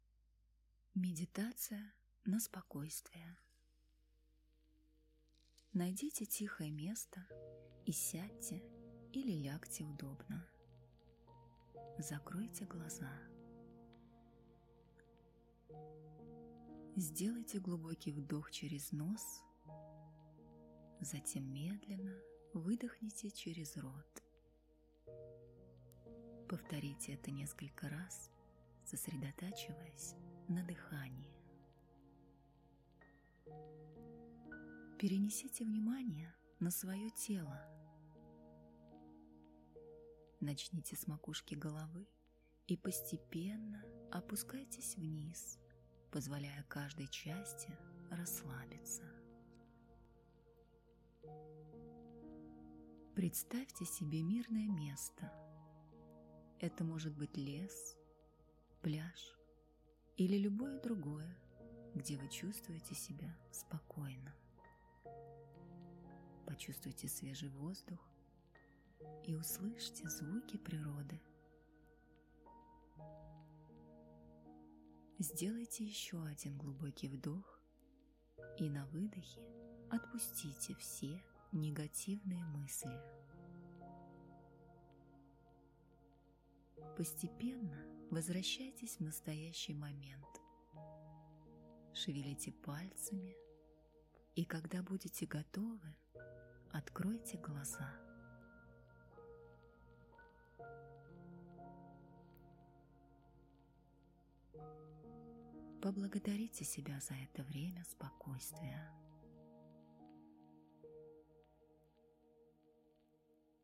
Медитация.
Жен, Другая/Молодой